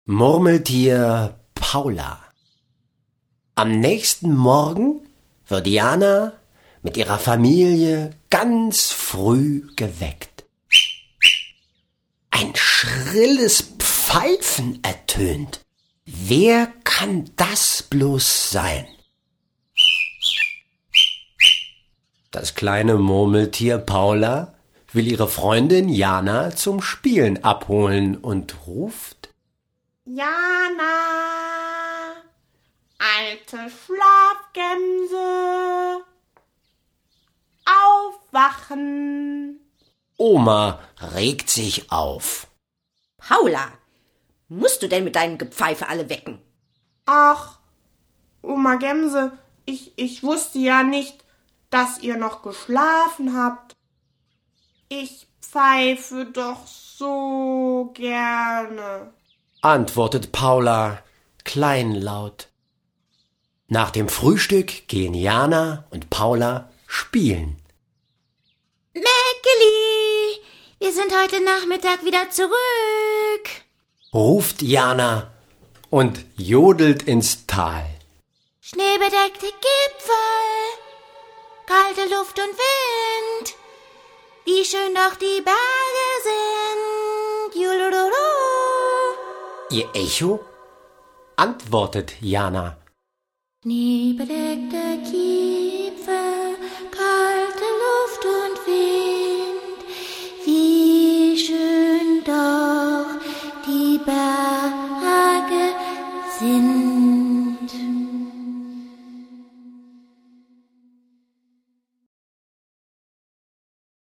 Hörspiele Band 4, 5, 6 und 7 mit Liedern der Serie „Marienkäfer Marie"